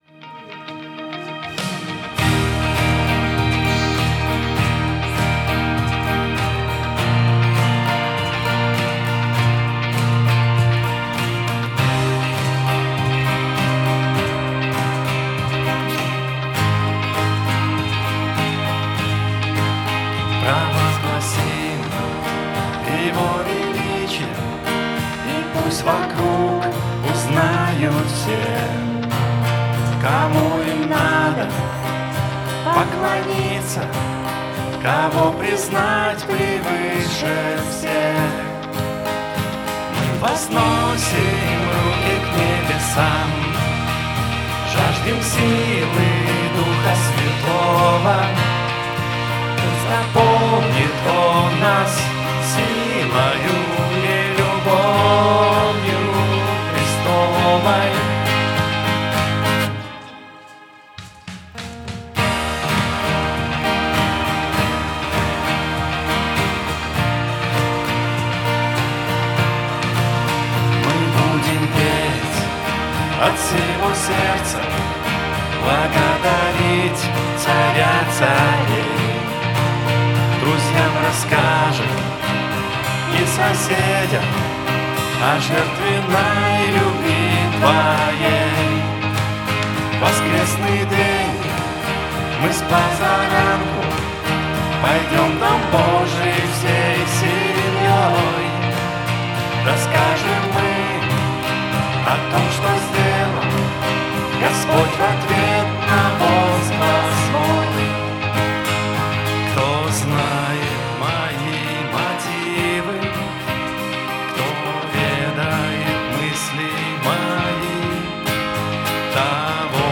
84 просмотра 164 прослушивания 0 скачиваний BPM: 100